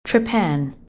Watch out! You may see this word with another pronunciation and its meaning is then different.